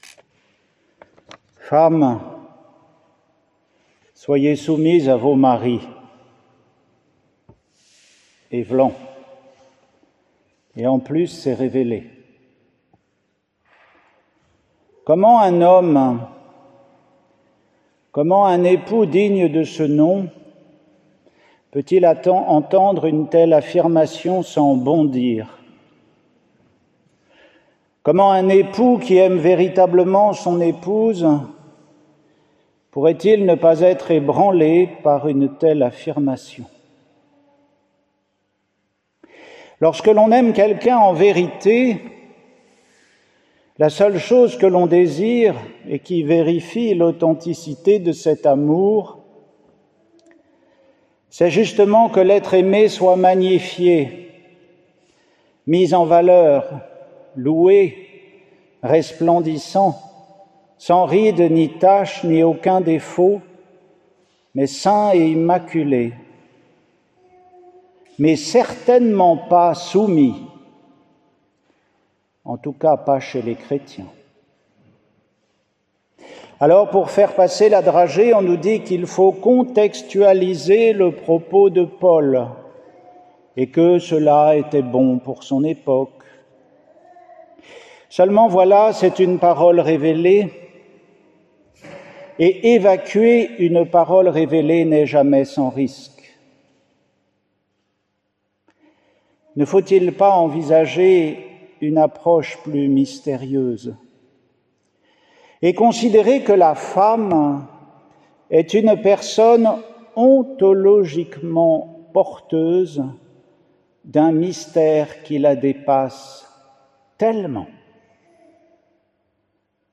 Homélie pour la messe du 21e dimanche du temps ordinaire, 25 août 2024